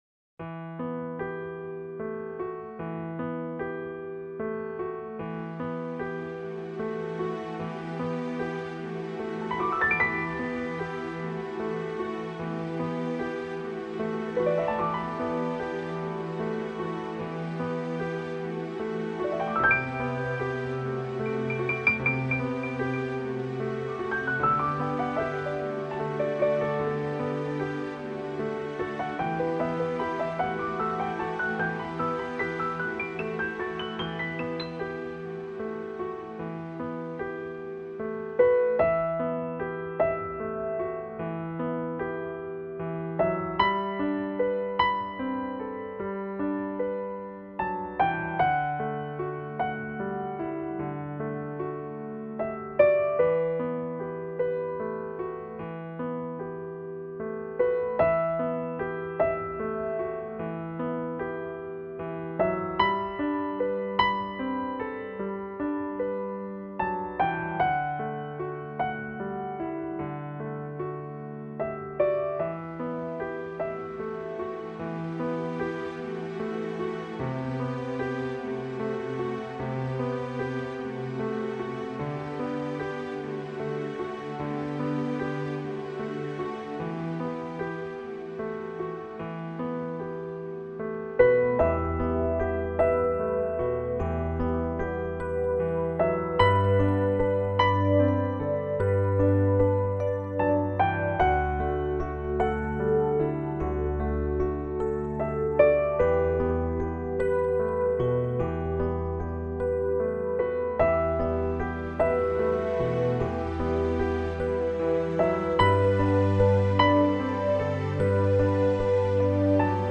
Инструментальная музыка Классическая музыка Классика